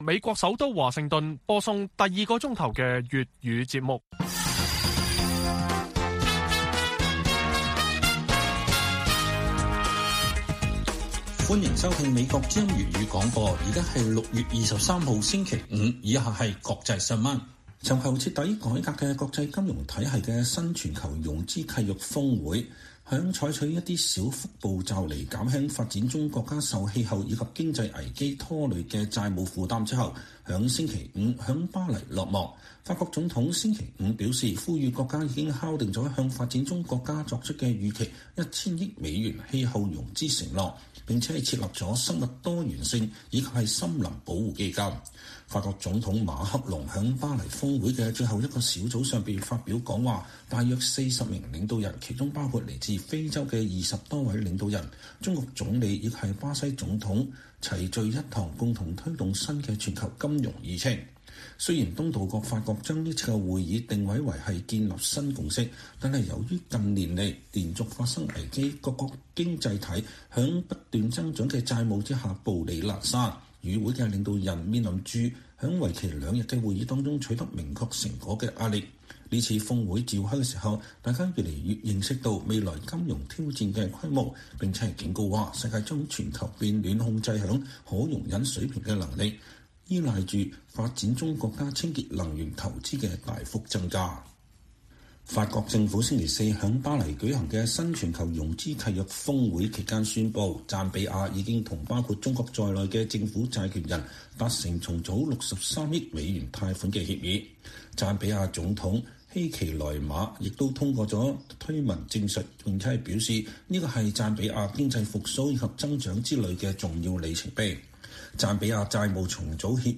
粵語新聞 晚上10-11點: 新全球融資契約峰會敲定向發展中國家提供1000億美元氣候融資承諾